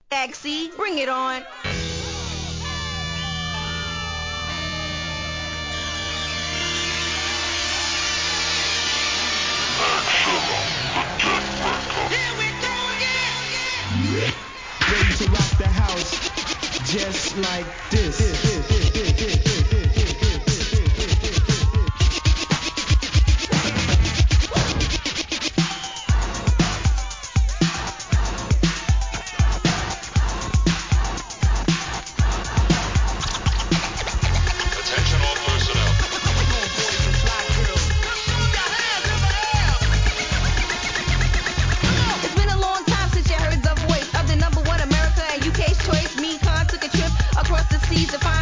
HIP HOP/R&B
REMIX!!